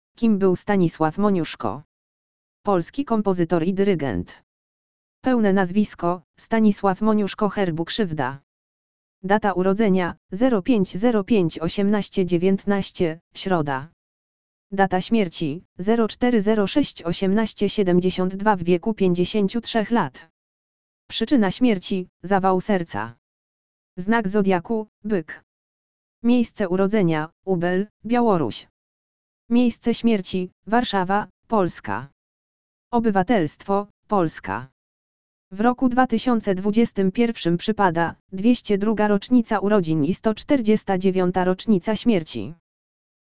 audio_lektor_urodziny_stanislawa_moniuszko.mp3